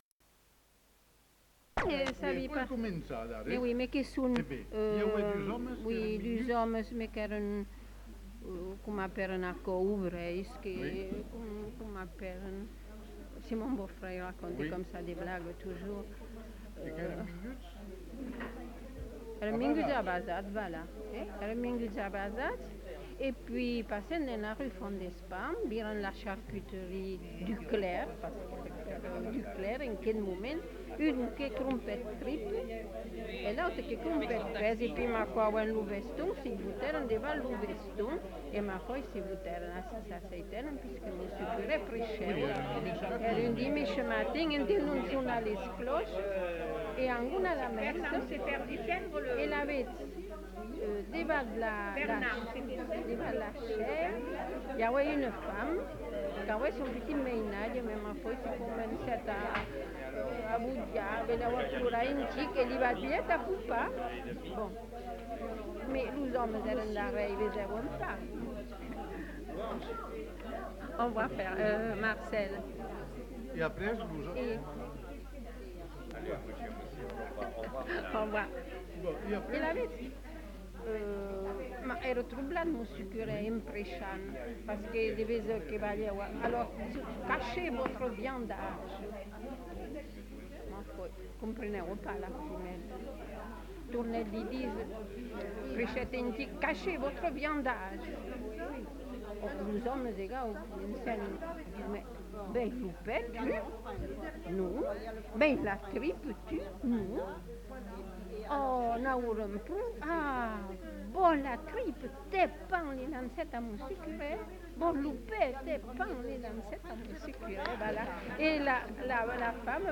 Aire culturelle : Bazadais
Lieu : Bazas
Genre : conte-légende-récit
Type de voix : voix de femme